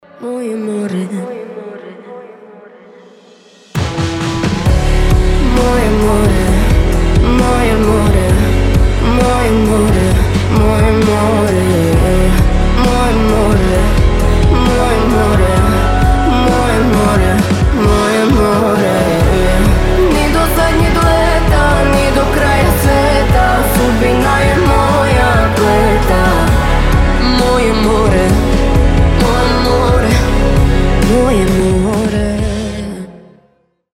красивые
женский вокал
Красивая песня на хорватском